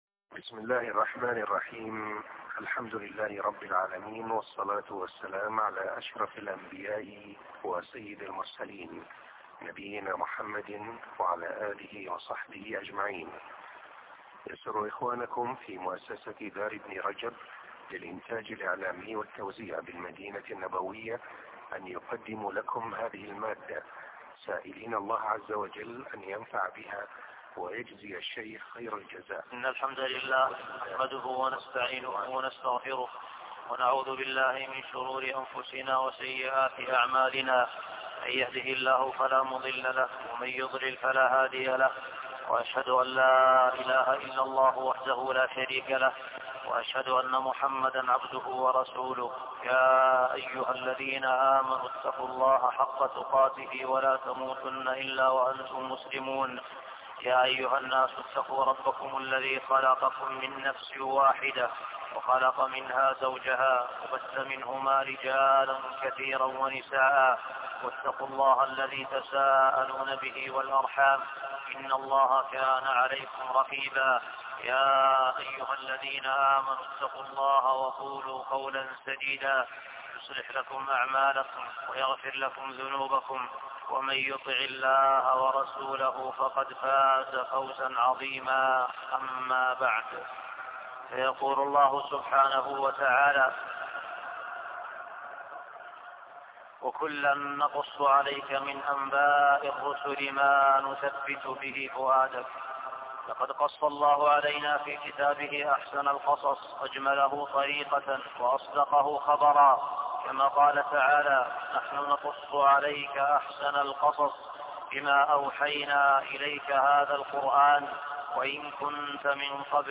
محاضره